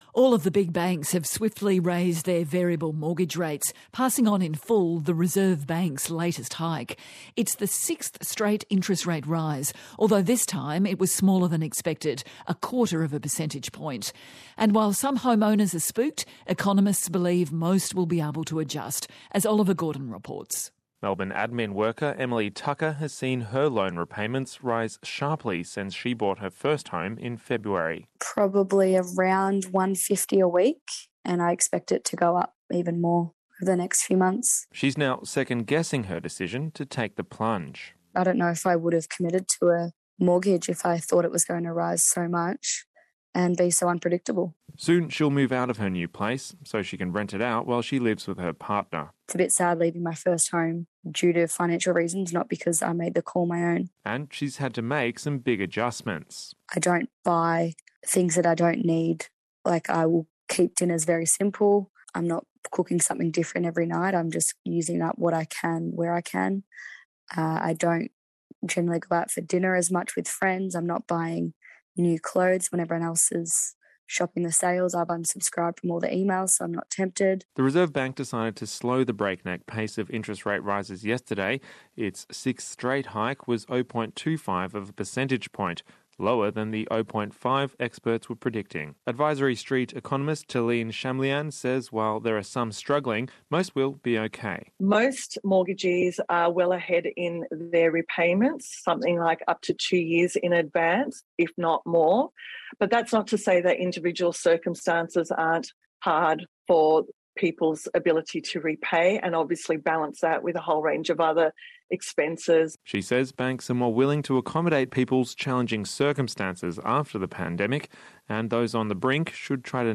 Interview of the latest rate rise on ABC RN program